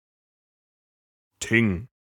Le thing (en vieux norrois et en islandais Þing, en allemand Thing [tɪŋ][1]
1. Prononciation en haut allemand (allemand standard) retranscrite selon la norme API.